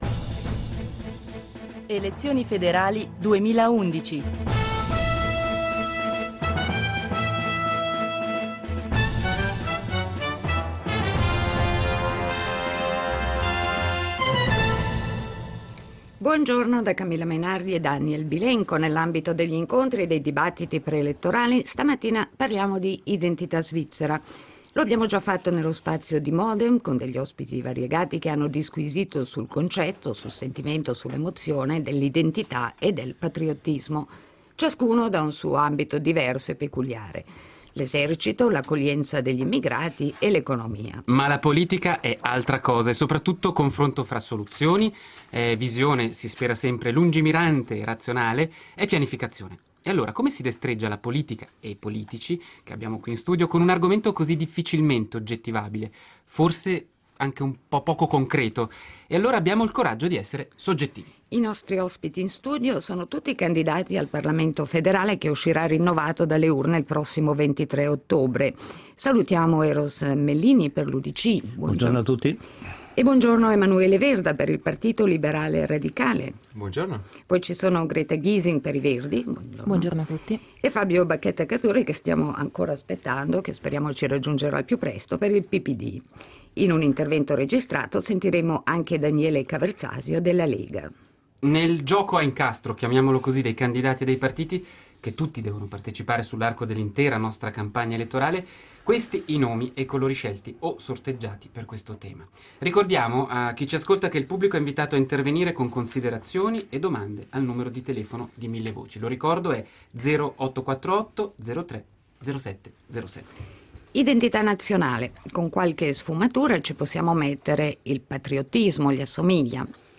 dibattiti